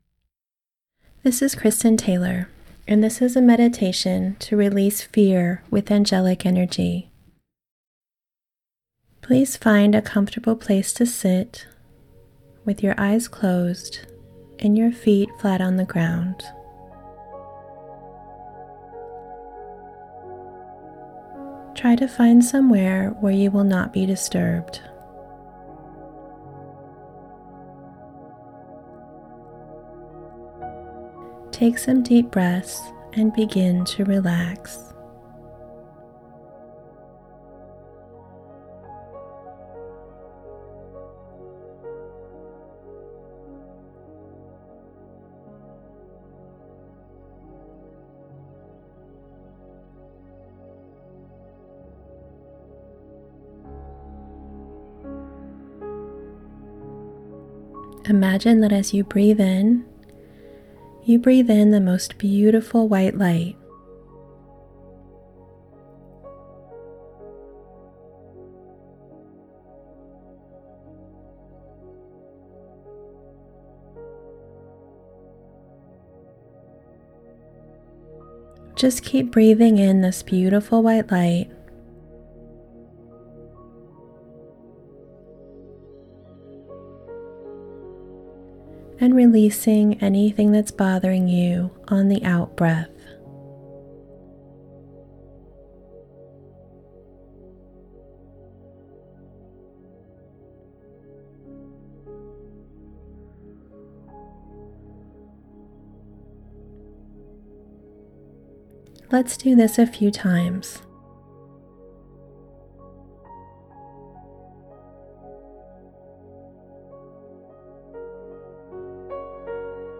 Fear-Releasing-Meditation-for-YouTube-March-2020.mp3